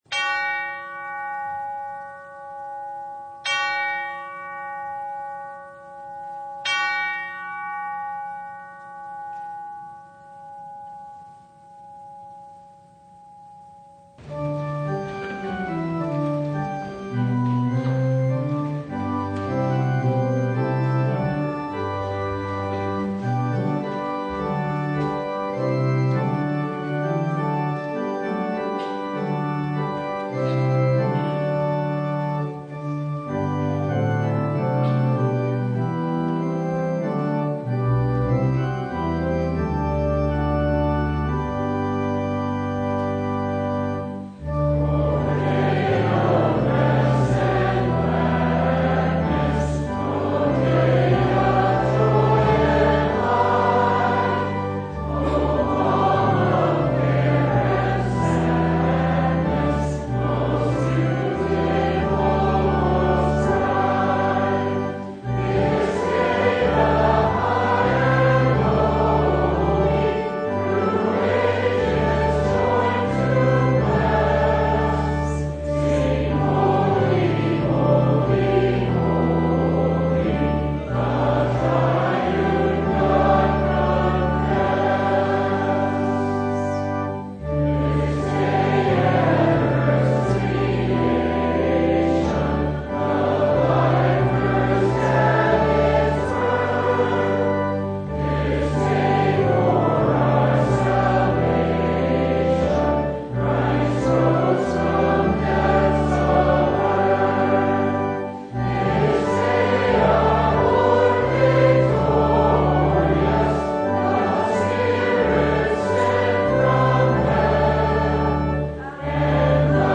Mark 10:17-22 Service Type: Sunday Riches